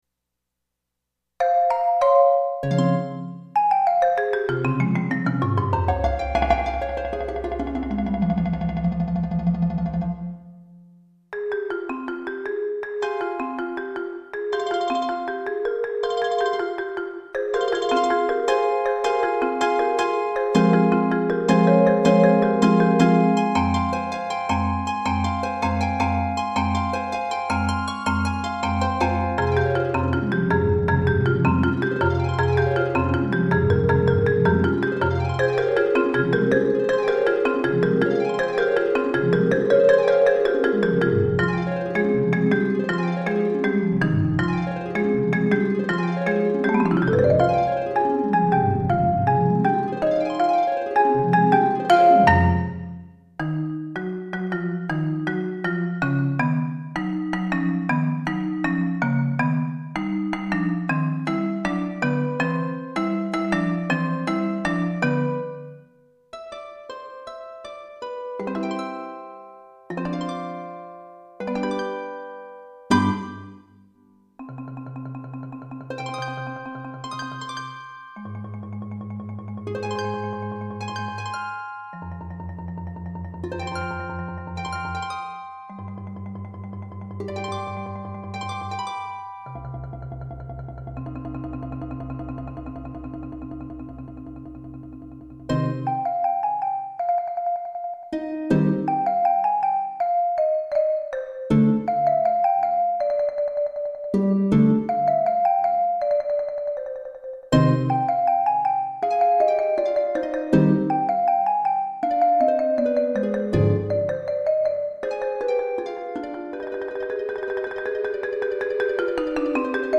MIDI-rendered.